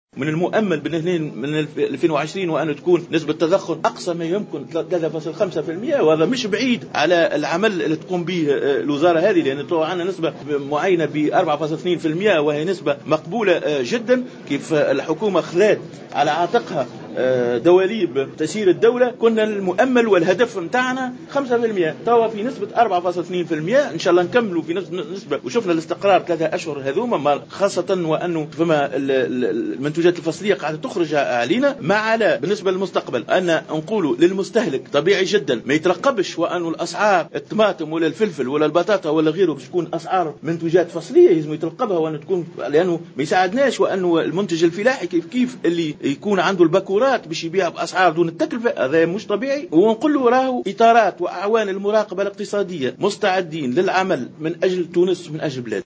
أكد وزير التجارة رضا لأحول في تصريح اعلامي على هامش إشرافه على إنطلاق حملة مراقبة صحية واقتصادية ببعض المطاعم اليوم الثلاثاء 6 أكتوبر 2015 أن نسبة التضخم في الأسعار تبلغ حاليا 4,2 بالمائة مشيرا إلى المجهودات المبذولة لتخفيضها إلى حدود 3.5 بالمائة في المخطط القادم وفق قوله.